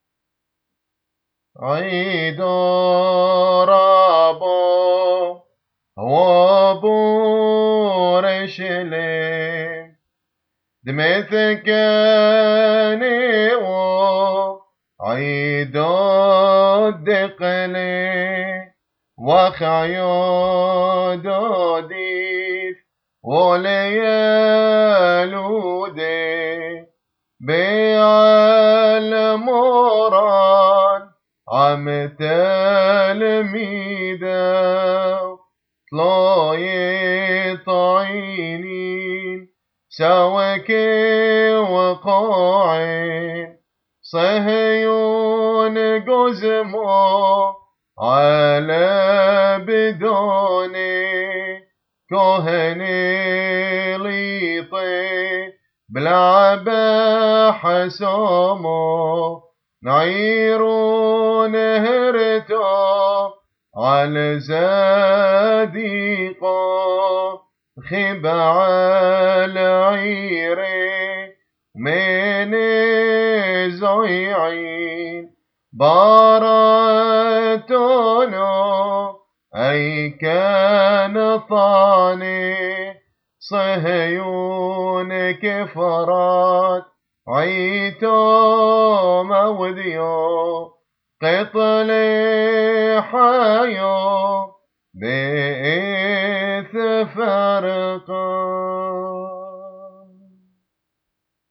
Palm Sunday Hymns